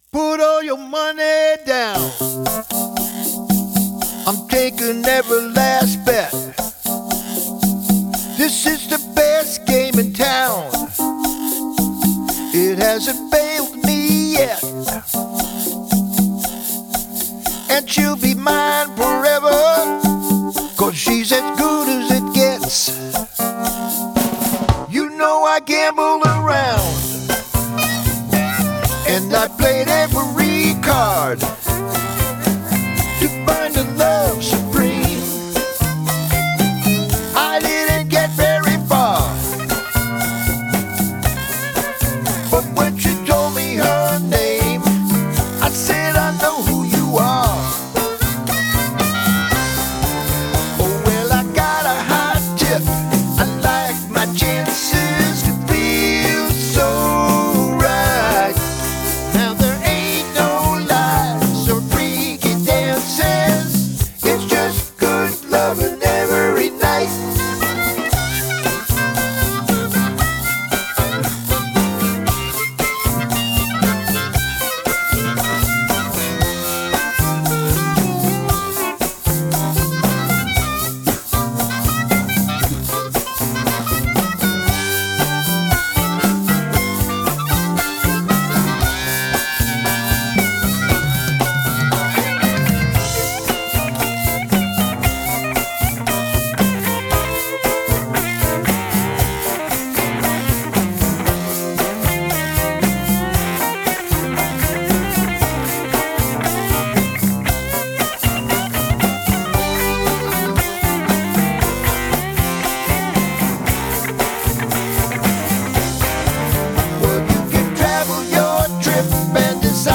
Genre: Americana/Blues
Keys – Roland/Wurlitzer patch